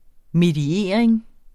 Udtale [ mediˈeɐ̯ˀeŋ ]